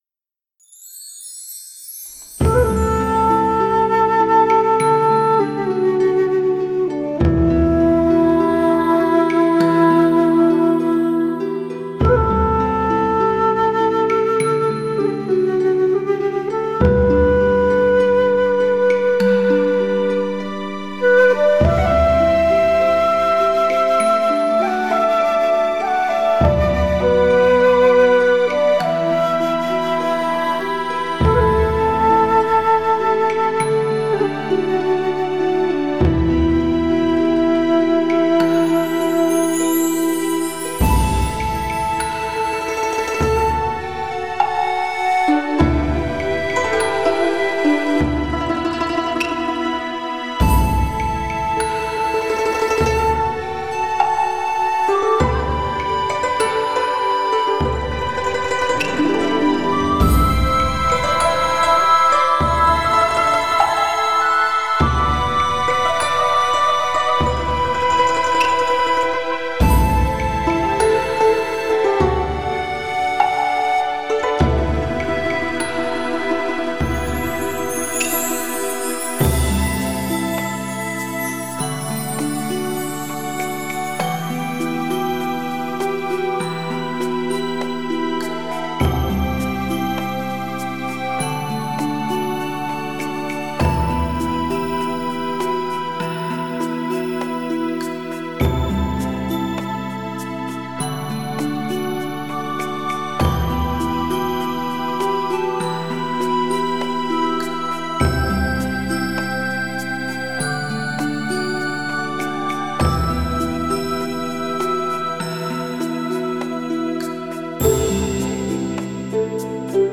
柔和的箫声带出优美的主题旋律，接着笛声悠扬和箫而来，相映成趣。
轻柔的舒缓的音乐真的很适合深夜里来听。